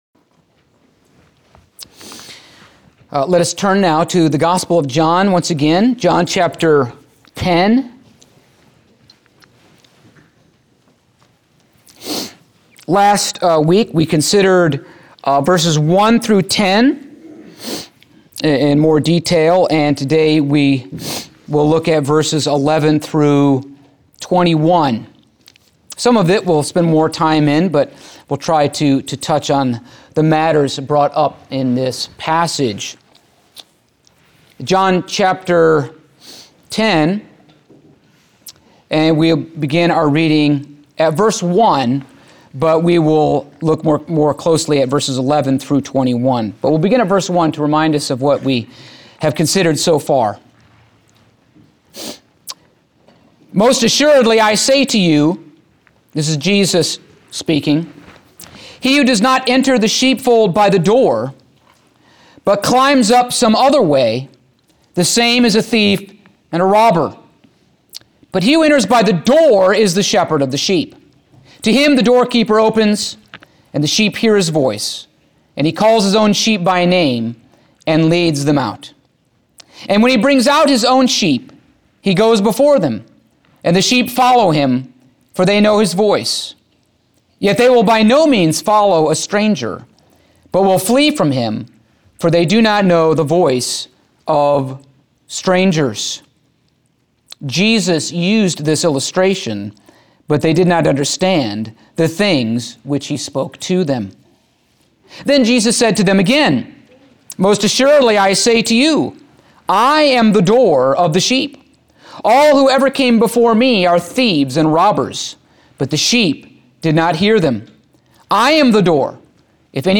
Passage: John 10:11-21 Service Type: Sunday Morning